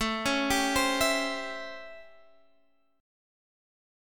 A7#9 Chord
Listen to A7#9 strummed